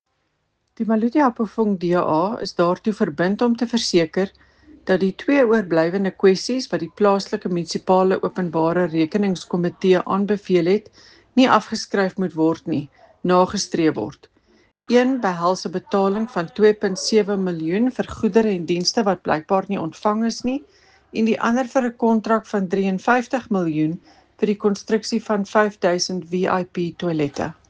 Afrikaans soundbite by Cllr Eleanor Quinta and